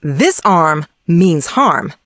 maisie_kill_vo_05.ogg